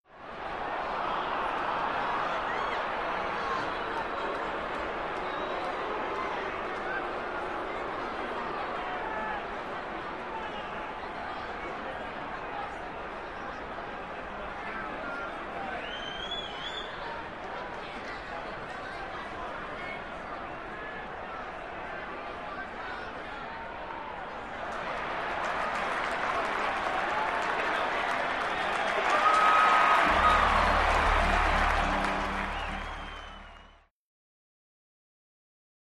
Arena Crowd; Crowd, Medium Active, Boos To Cheer.